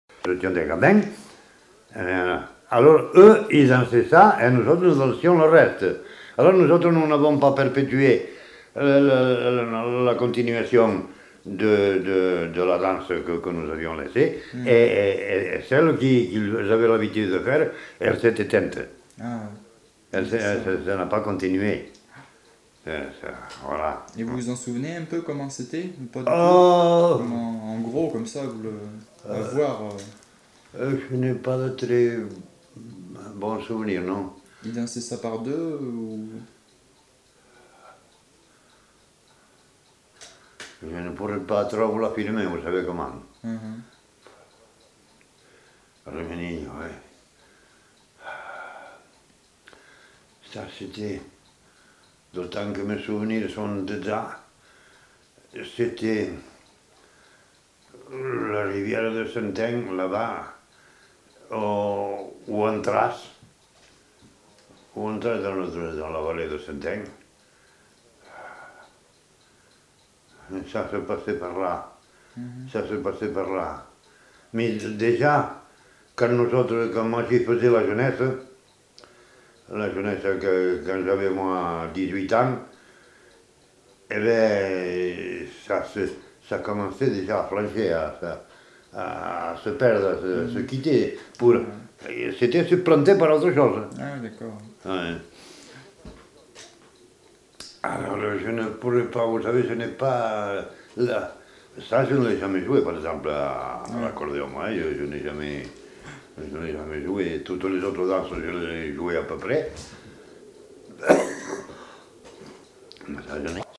Aire culturelle : Couserans
Lieu : Uchentein
Genre : témoignage thématique